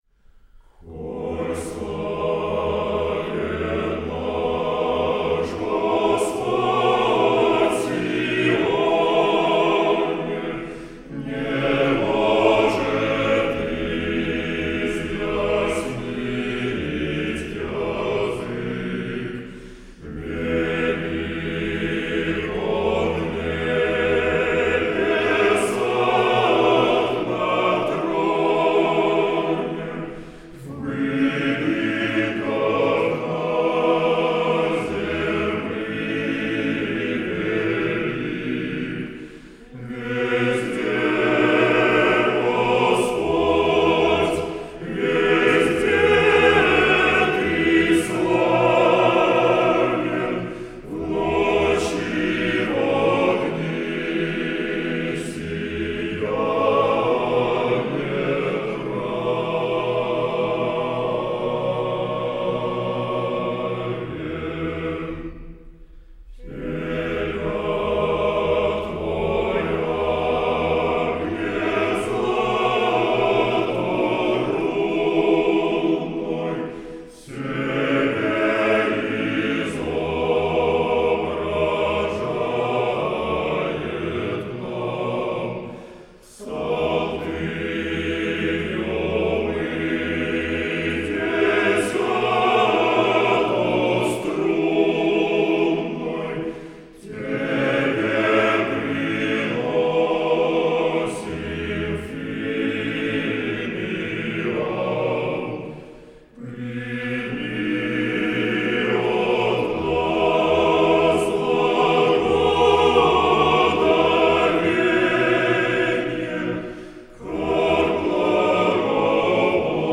А-капелло Скачать